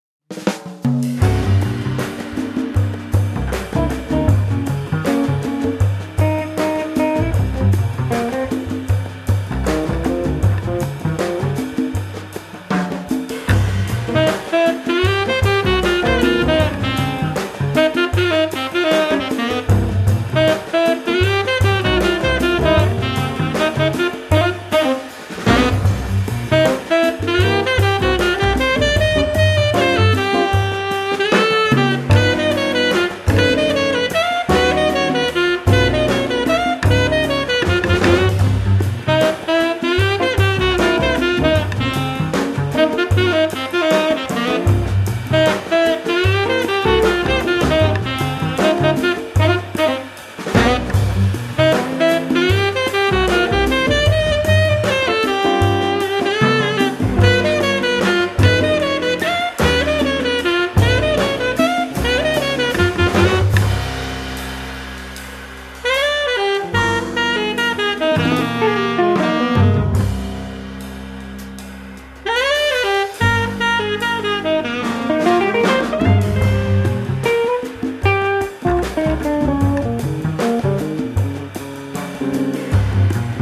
saxophones
guitar
bass
drums